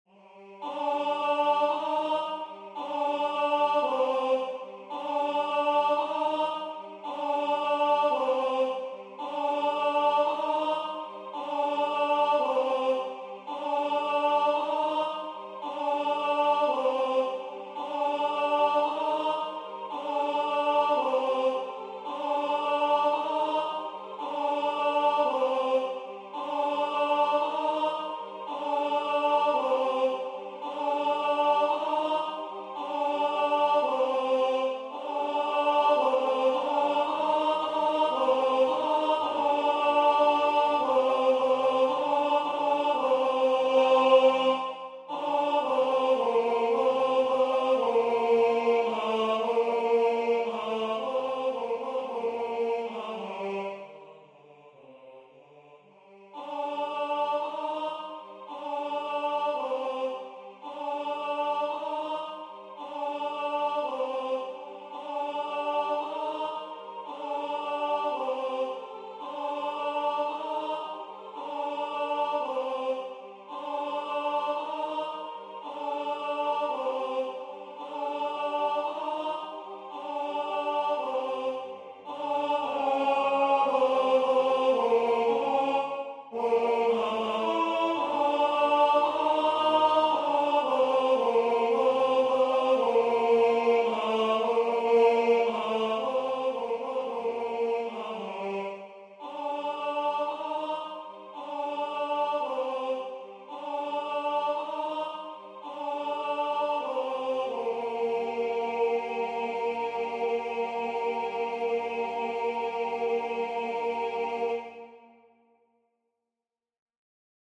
Tenor Voix Synth